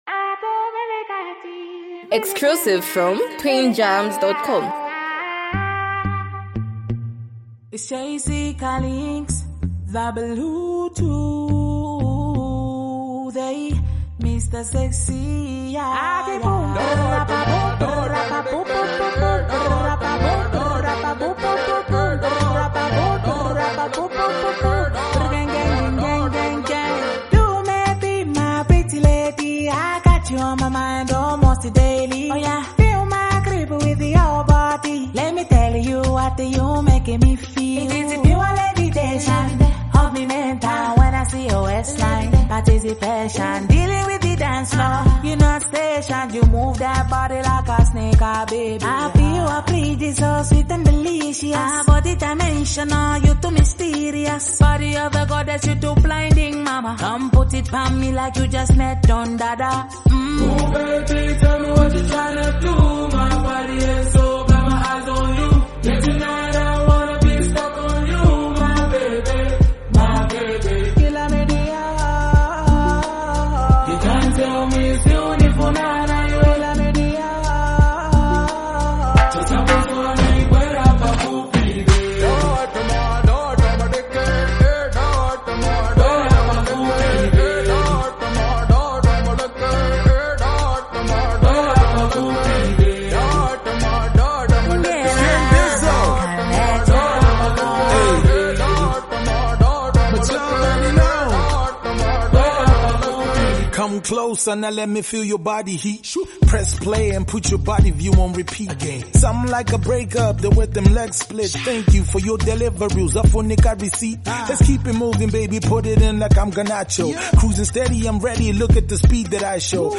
smooth, feel-good love song